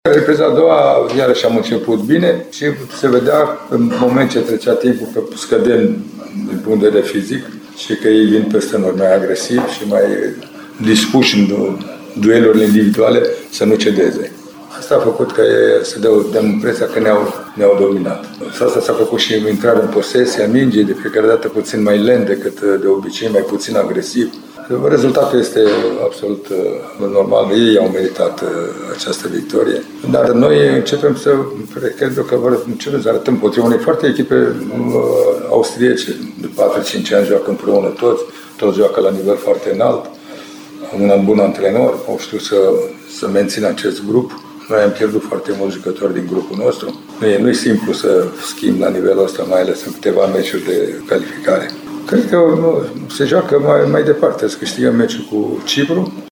Lucescu a găsit scuze și pentru repriza a doua, una în care gazdele doar „au lăsat impresia că ne-au dominat”:
2-Lucescu-repriza-a-doua.mp3